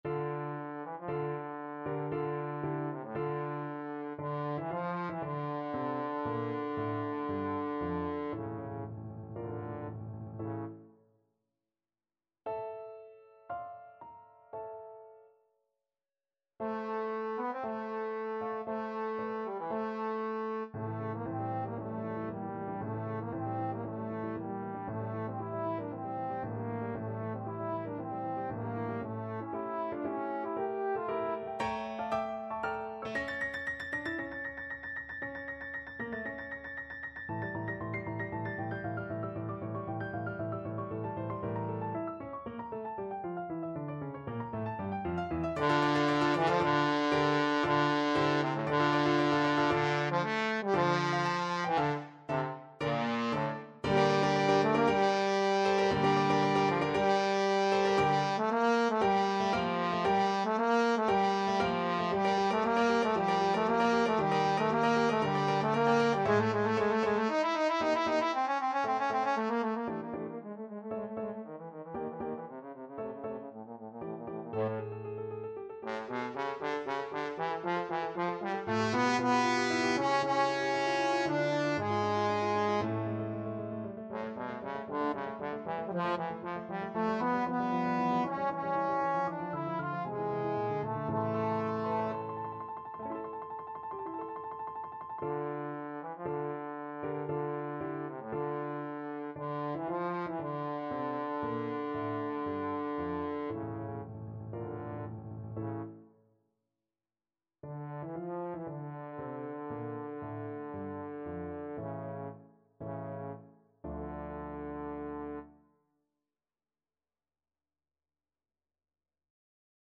Classical Dvořák, Antonín Concerto for Cello Op.104, 1st Movement Main Theme Trombone version
Trombone
4/4 (View more 4/4 Music)
D minor (Sounding Pitch) (View more D minor Music for Trombone )
Allegro =116 (View more music marked Allegro)
Classical (View more Classical Trombone Music)